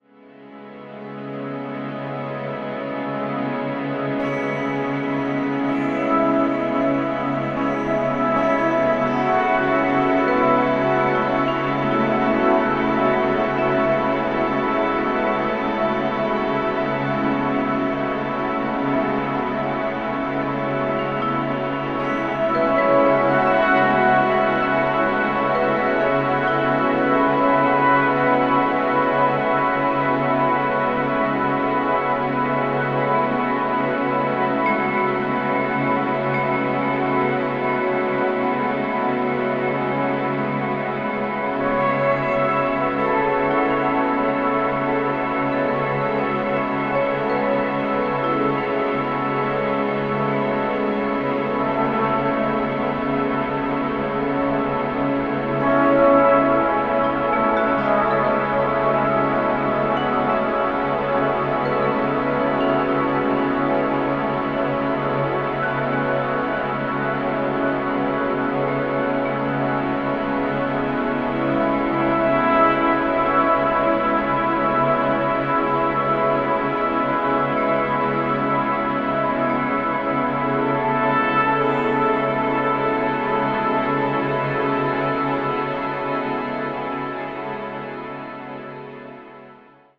豊かに反復するドローン、細やかなピアノの残響、丹念に作り上げられた音像と１曲１曲がドラマチックに満ちてゆく。